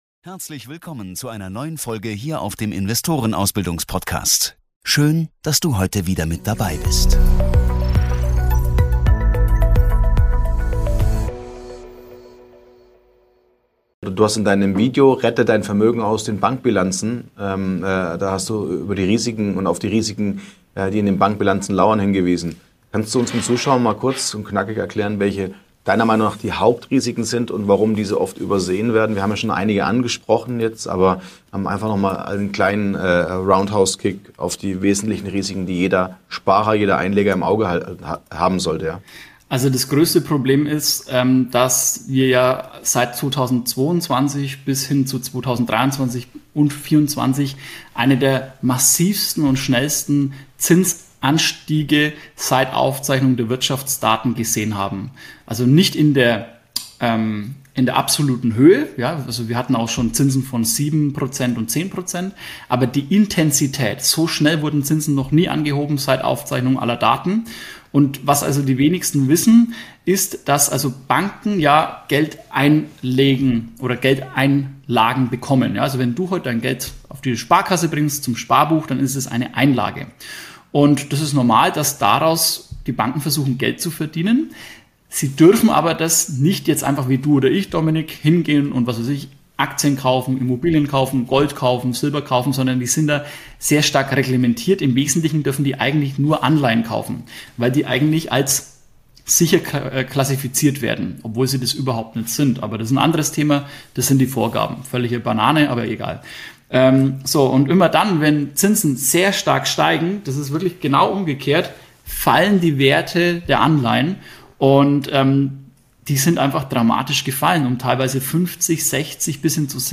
In diesem Gespräch